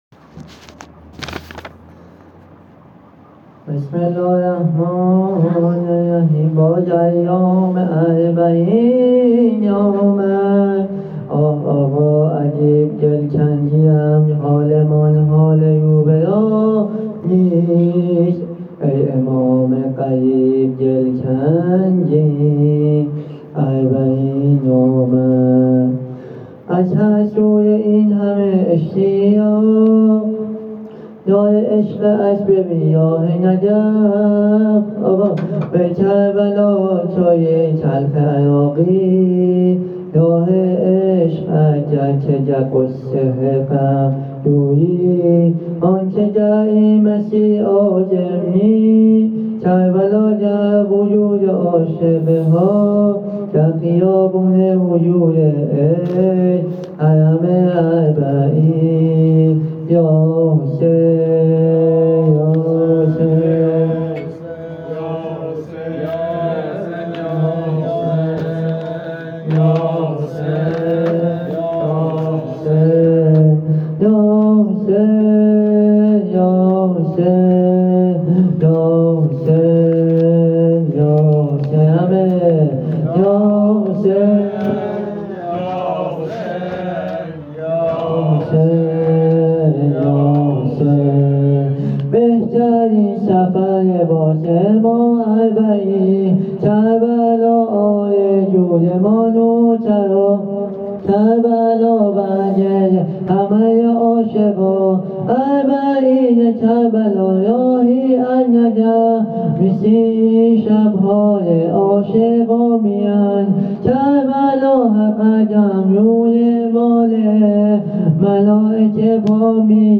زمینه اربعین حسینی
هیئت ابافضل العباس امجدیه تهران